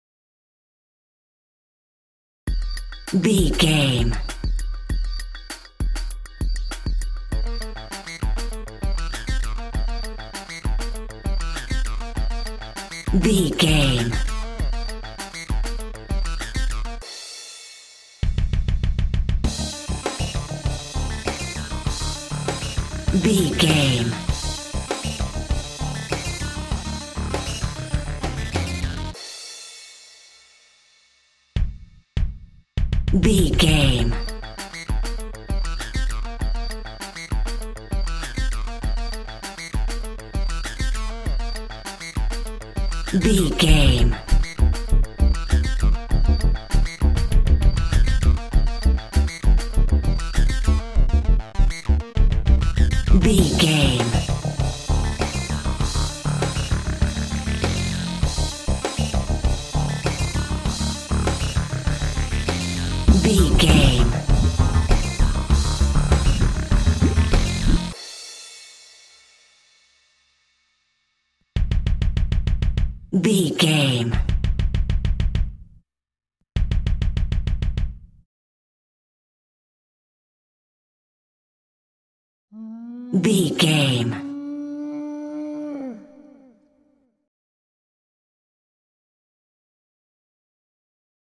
Sci Fi Video Game Music.
Epic / Action
Fast paced
Aeolian/Minor
intense
futuristic
driving
synthesiser
drum machine
electronic
techno
trance
industrial
glitch
synth leads
synth bass